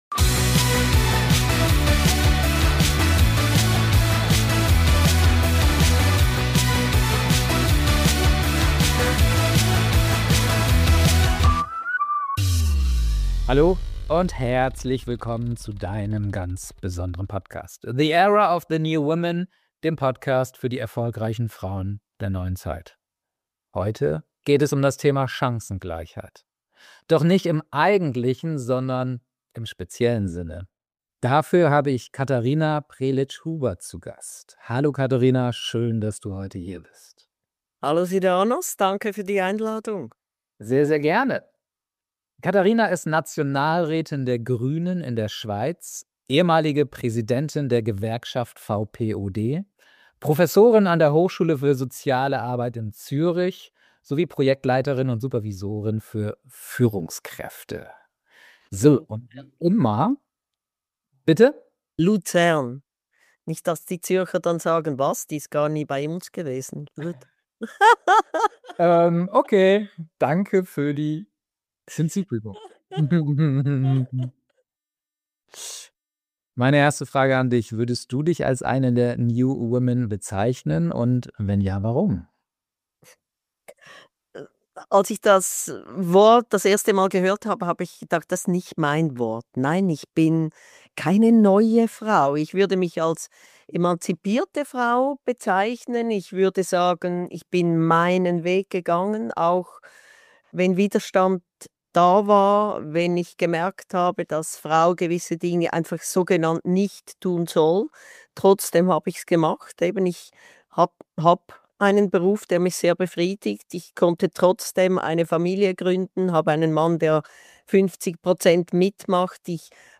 #081 Chancengleichheit ist kein Luxus – sie ist Haltung. Das Interview mit Nationalrätin Katharina Prelicz-Huber ~ The Era of the New Women Podcast